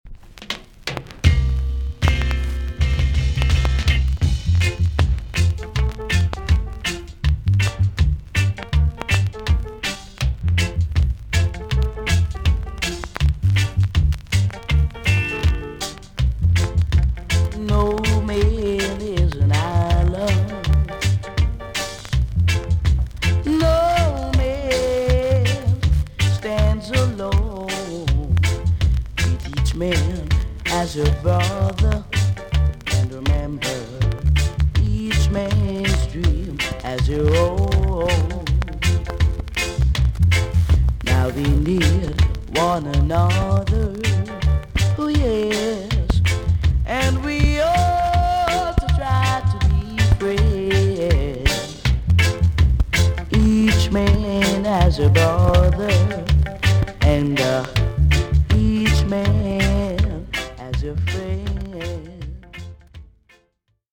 TOP >SKA & ROCKSTEADY
VG ok 全体的にプチノイズが入る箇所があります。